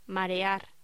Locución: Marear